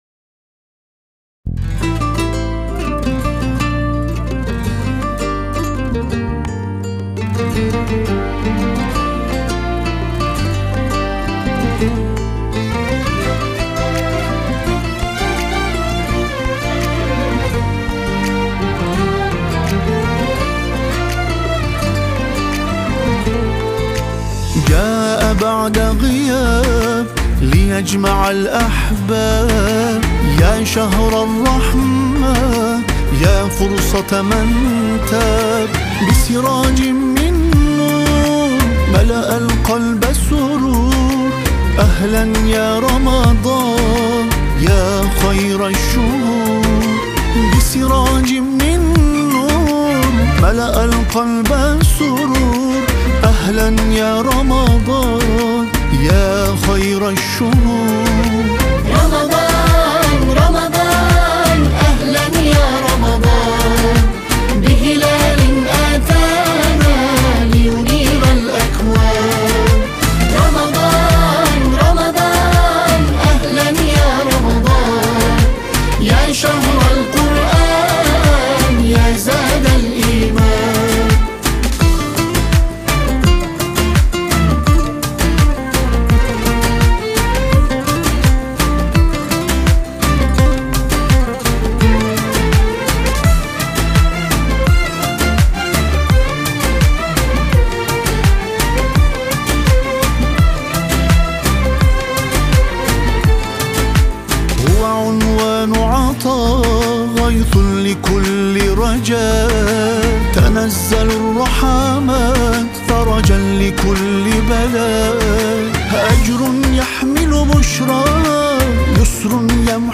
Only Vocals Version